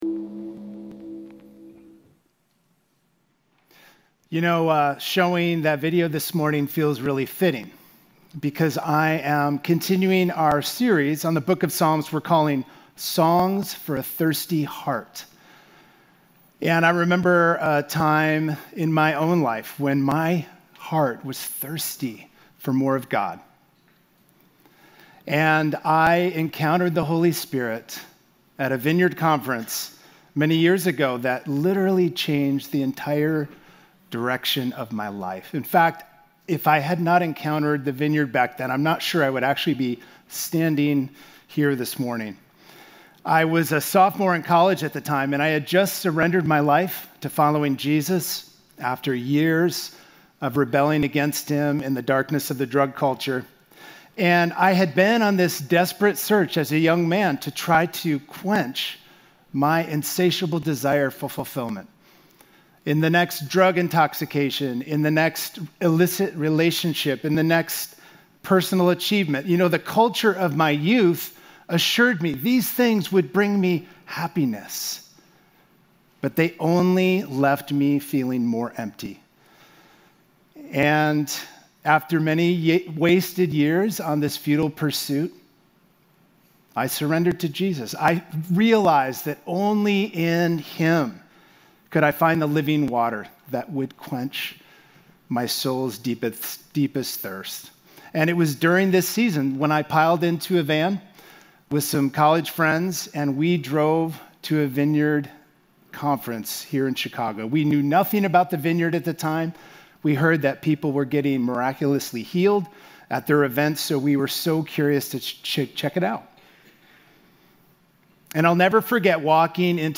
message. Psalm 13; Psalm 62:8; 1 Peter 5:8